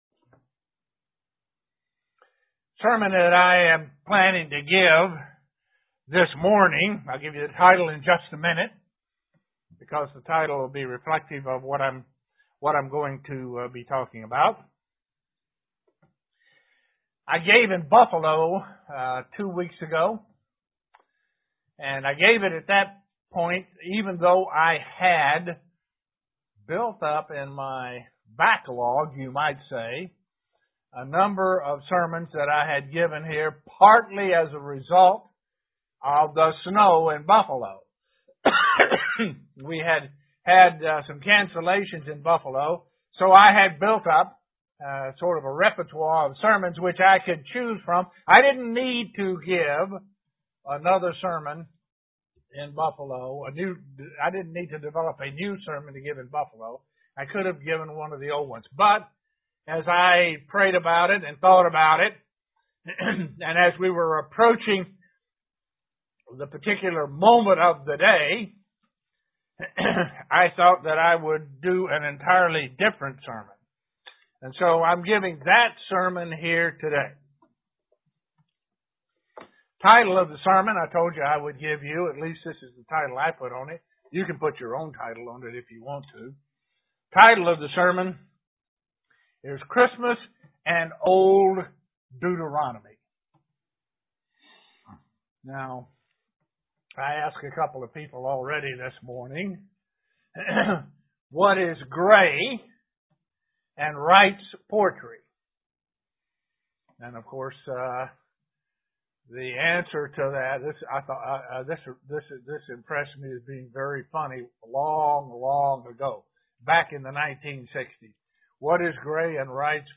Given in Elmira, NY Buffalo, NY
UCG Sermon Studying the bible?